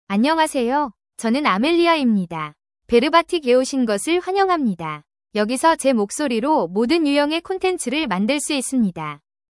AmeliaFemale Korean AI voice
Amelia is a female AI voice for Korean (Korea).
Voice sample
Listen to Amelia's female Korean voice.
Female